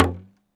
Index of /musicradar/essential-drumkit-samples/Hand Drums Kit
Hand Talking Drum 02.wav